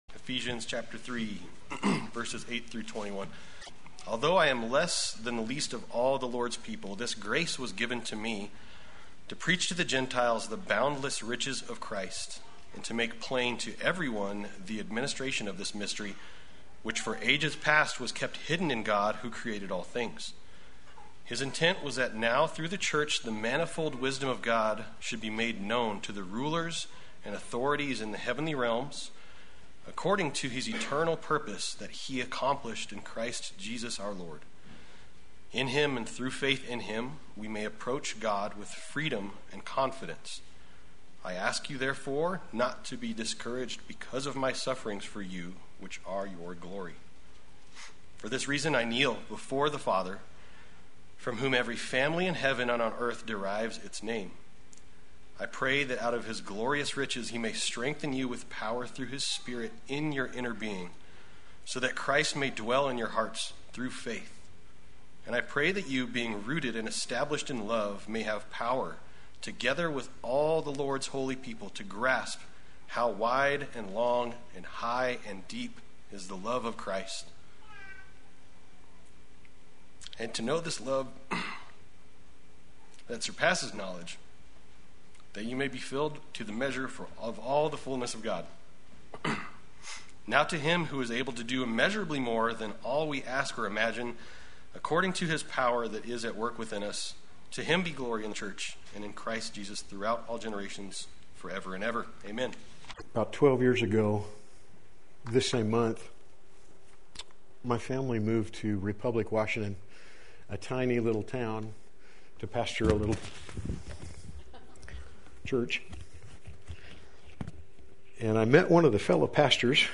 Play Sermon Get HCF Teaching Automatically.
Two Reasons to Love the Church Sunday Worship